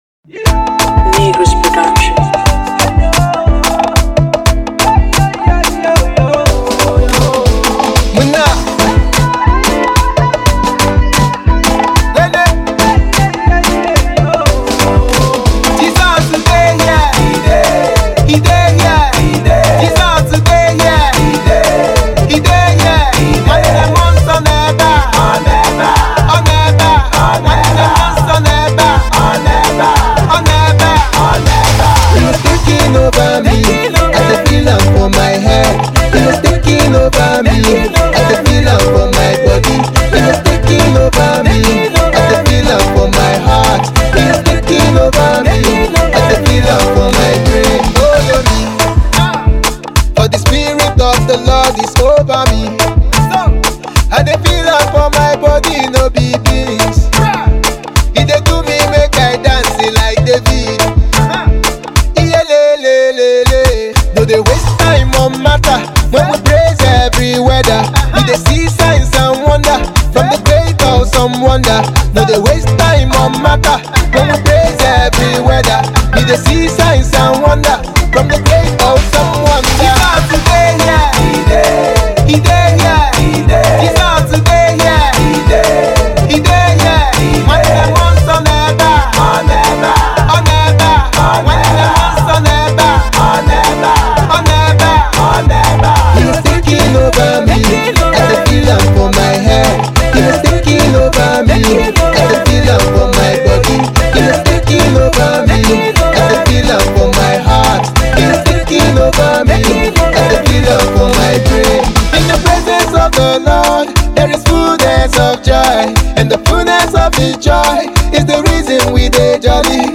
urban Gospel